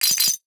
NOTIFICATION_Glass_11_mono.wav